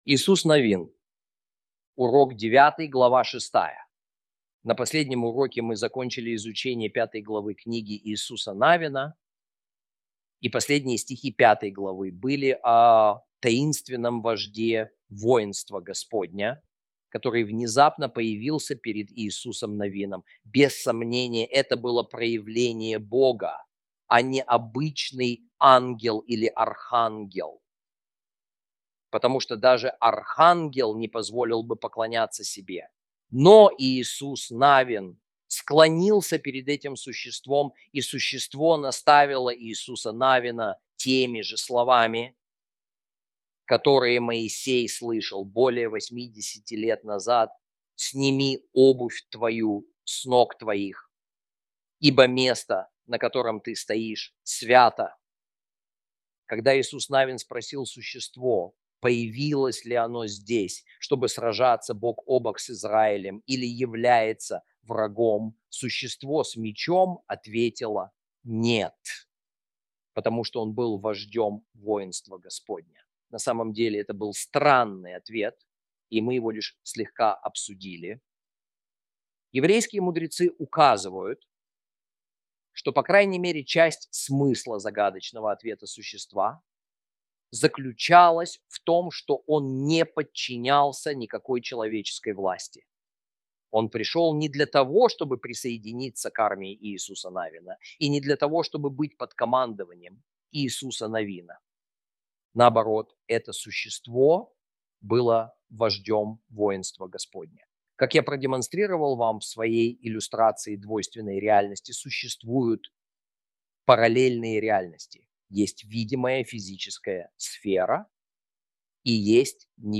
Video, audio and textual lessons